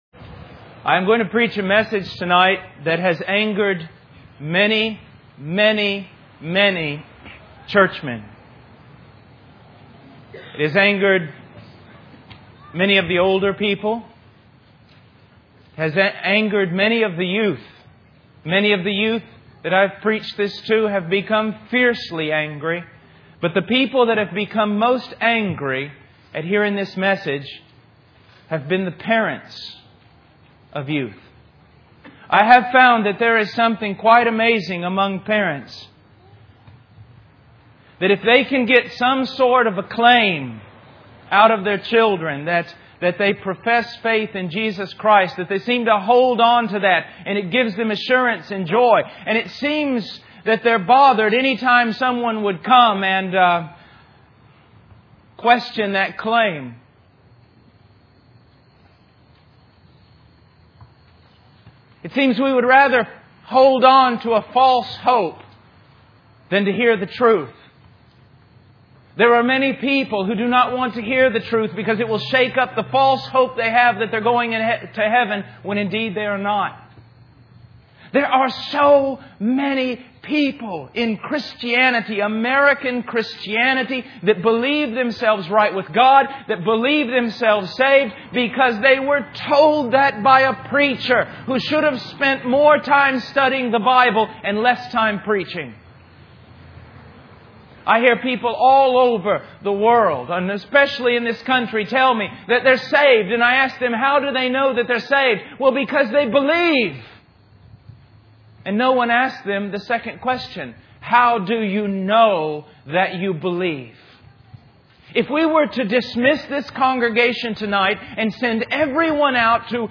A Sermon That Has Angered Many.mp3